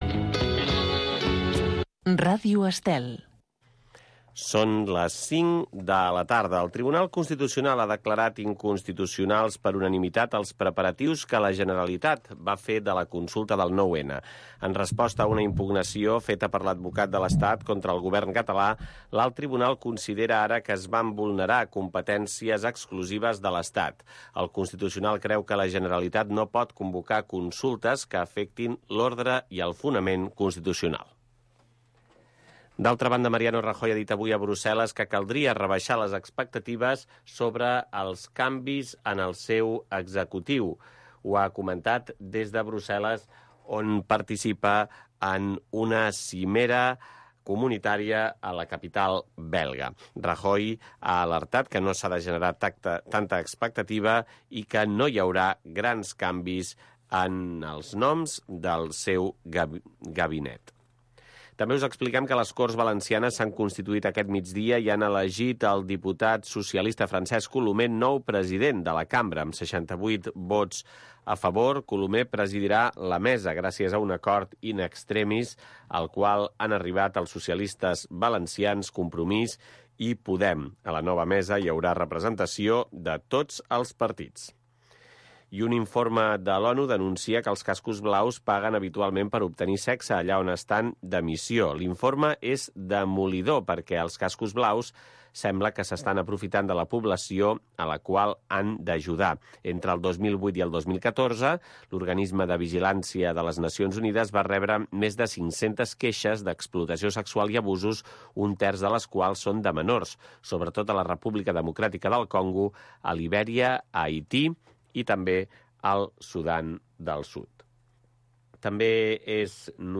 Estem amb tu. Magazín cultural de tarda.